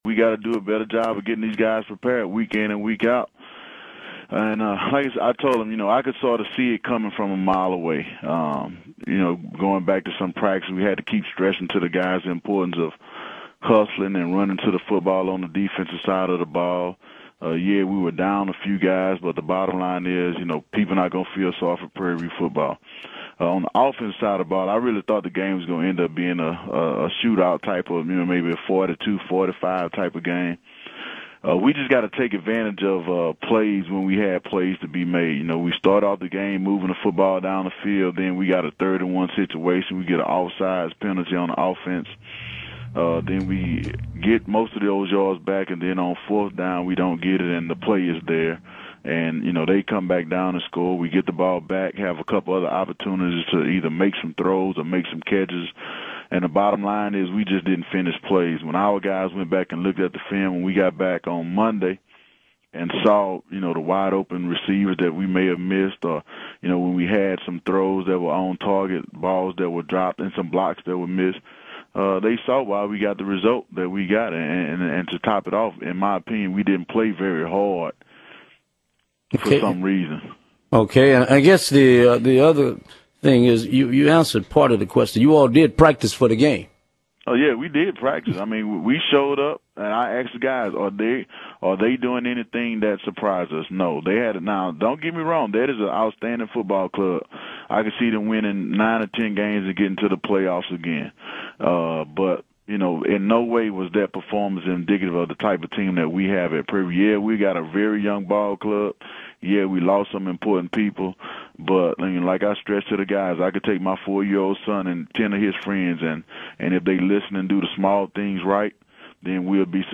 live_broadcast4.mp3